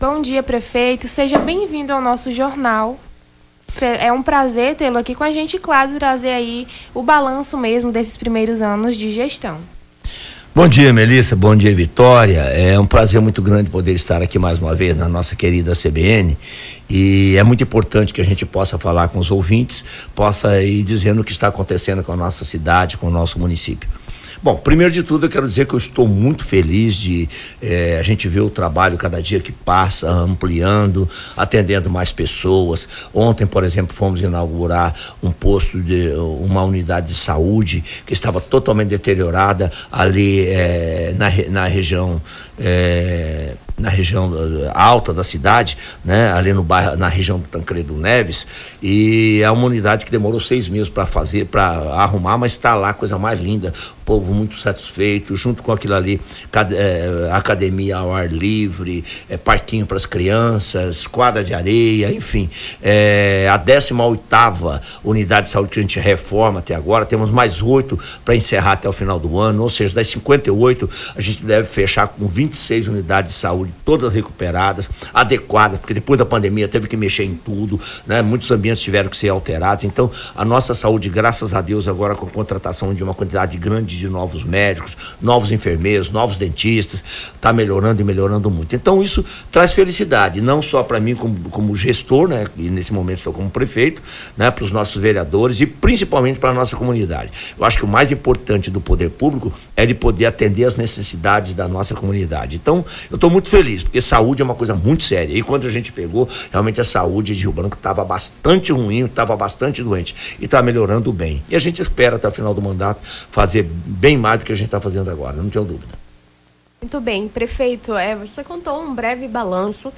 Prefeito de Rio Branco fala sobre balanço da gestão municipal